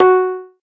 noteblock_pling.wav